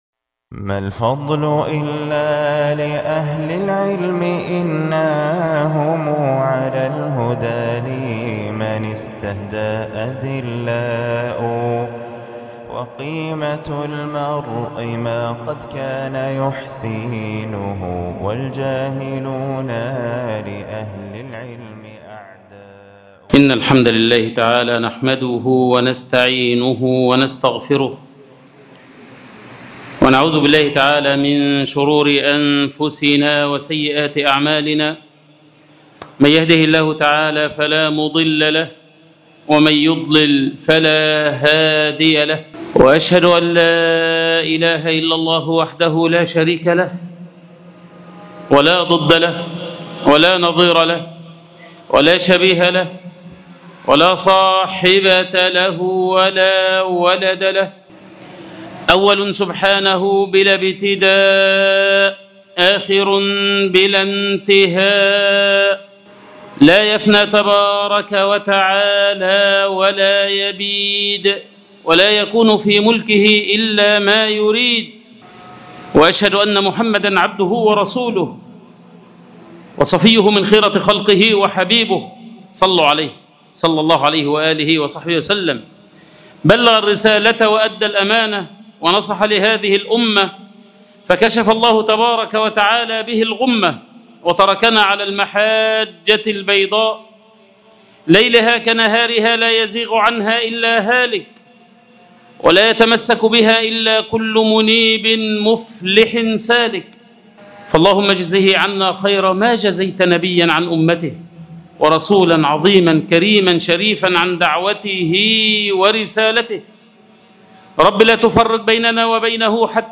خطبة الجمعة_ قصة آدم عليه السلام